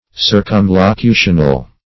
Search Result for " circumlocutional" : The Collaborative International Dictionary of English v.0.48: Circumlocutional \Cir`cum*lo*cu"tion*al\, a. Relating to, or consisting of, circumlocutions; periphrastic; circuitous.
circumlocutional.mp3